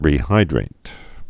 (rē-hīdrāt)